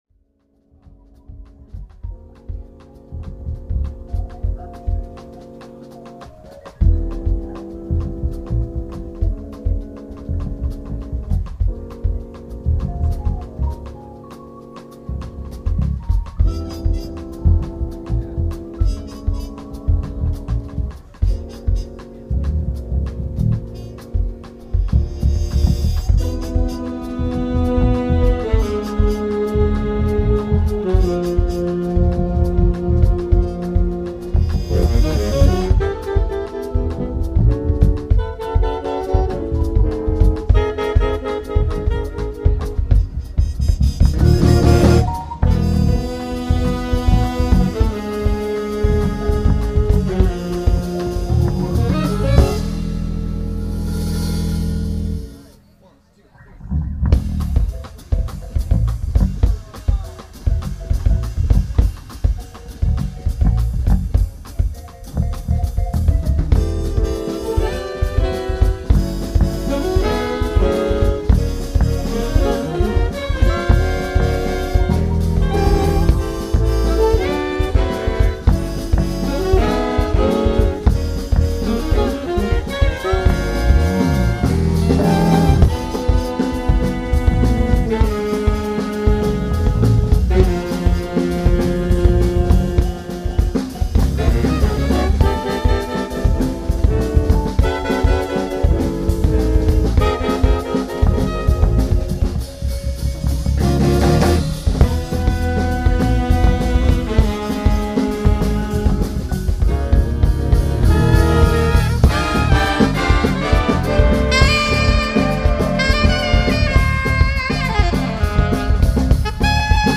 jazz-electronica
live loops and sampling